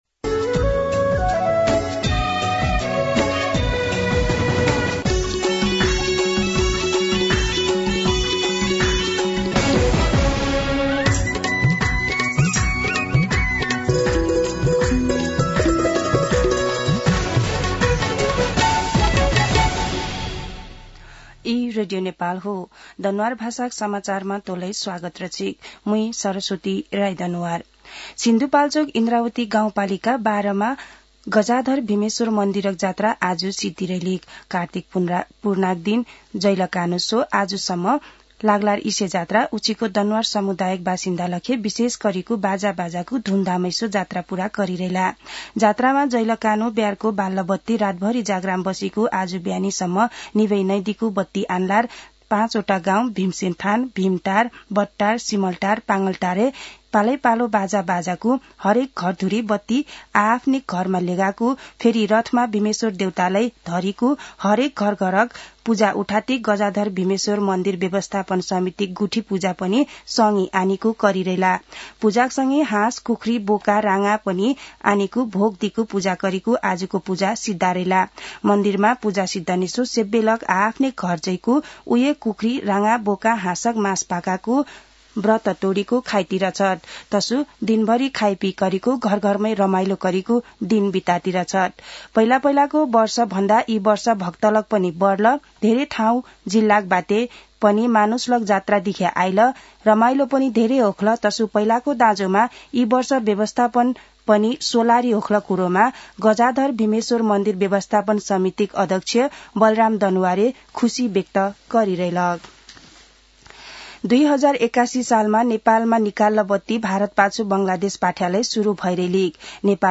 दनुवार भाषामा समाचार : १ मंसिर , २०८१
Danuwar-News.mp3